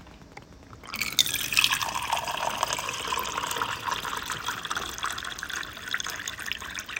Liquid-Dispensing like 0